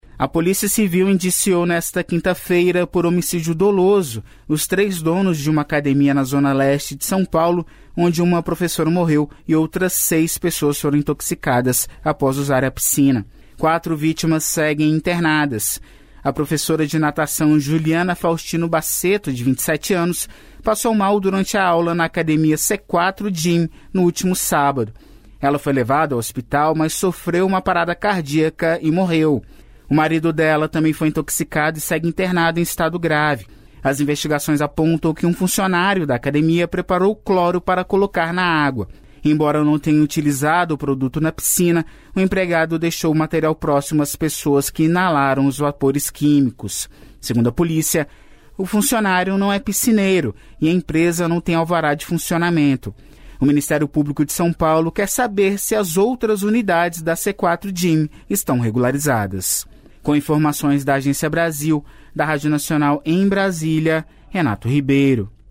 Boletim de notícias veiculado de hora em hora, com duração média de até quatro minutos.